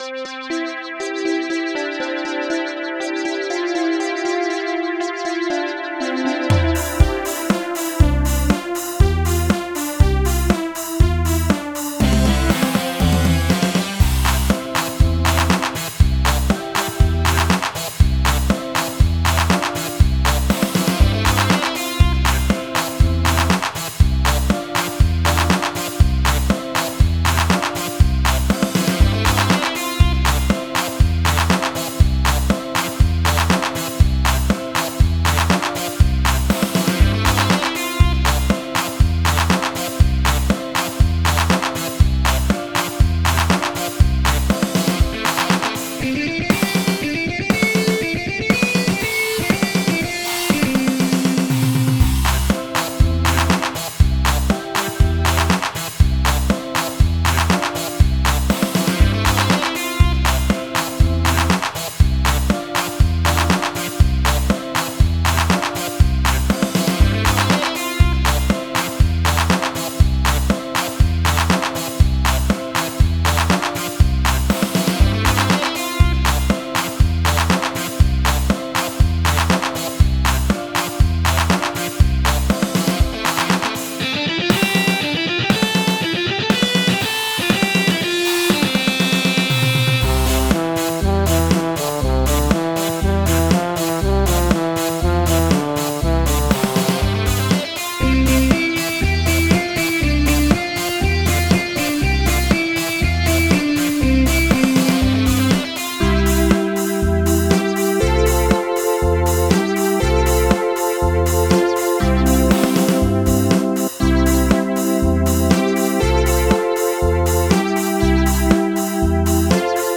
с помощью компьютера и синтезатора
Минусовка (для караоке)